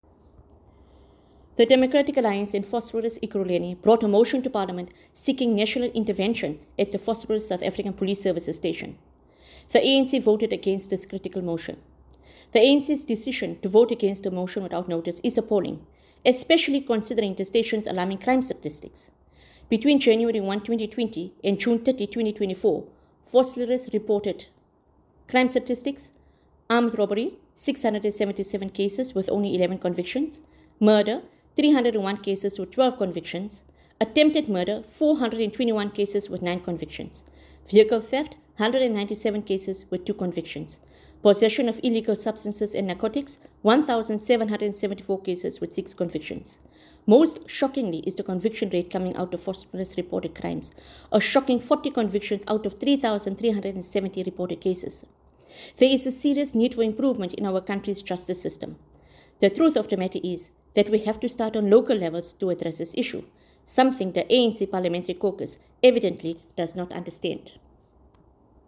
Note to Editors: Please find an English soundbite by Haseena Ismail MP